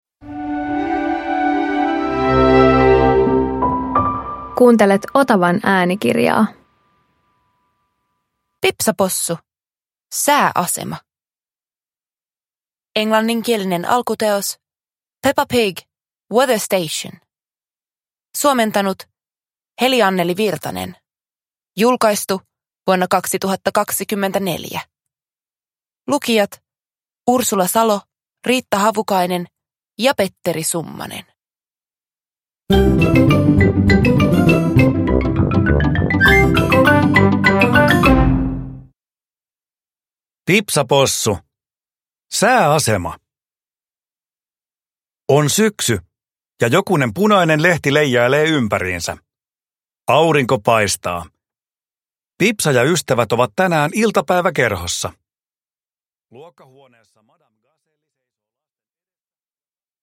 Pipsa Possu - Sääasema – Ljudbok